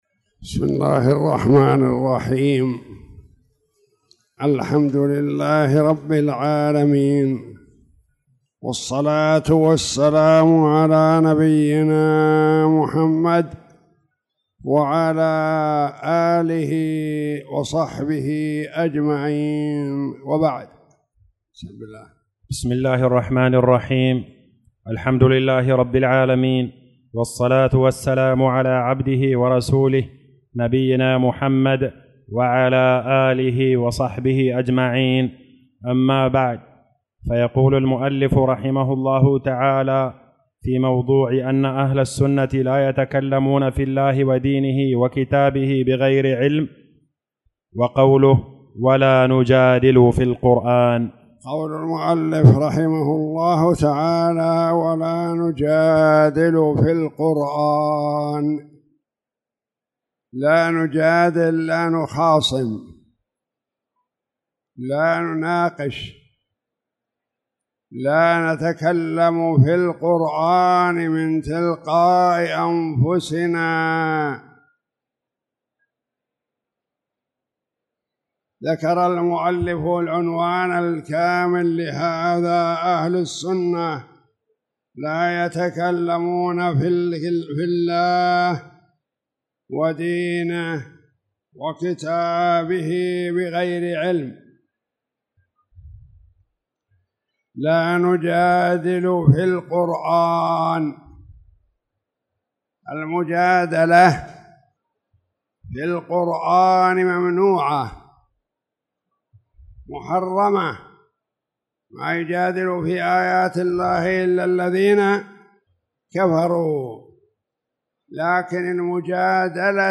تاريخ النشر ٢ شعبان ١٤٣٧ هـ المكان: المسجد الحرام الشيخ